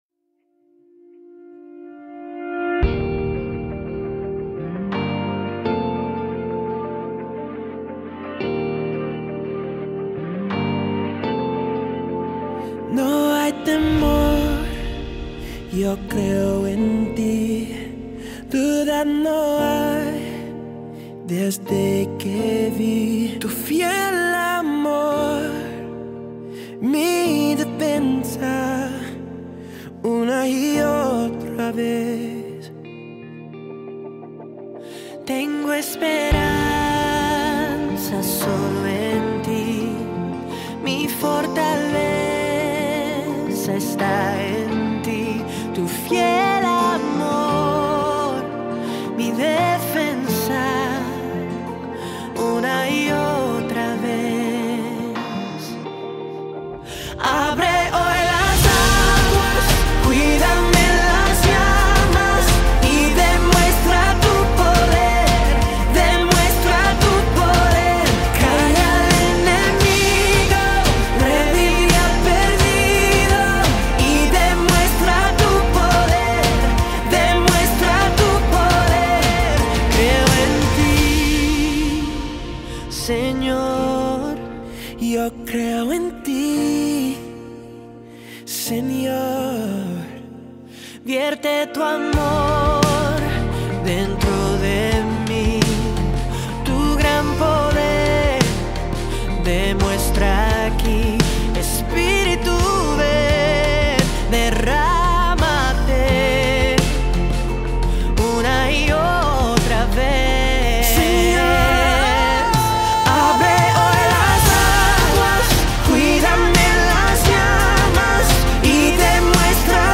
547 просмотров 423 прослушивания 43 скачивания BPM: 172